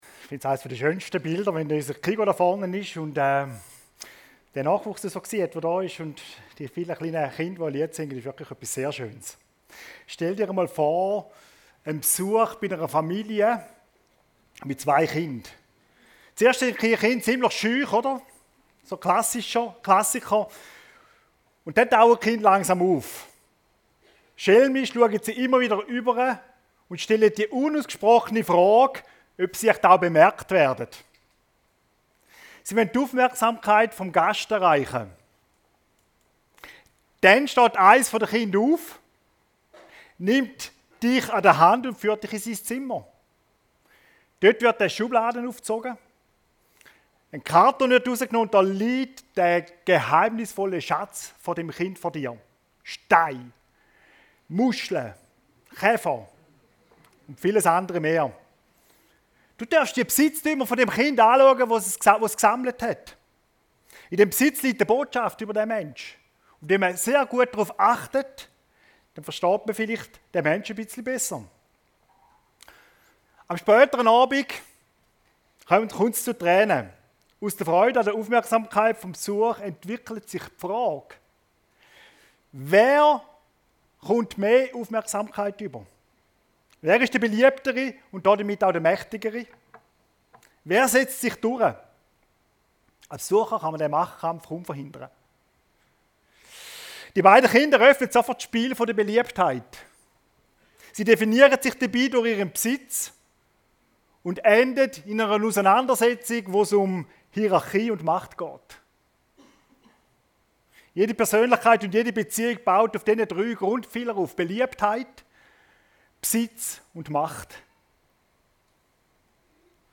Von Motoren und Bremsen - seetal chile Predigten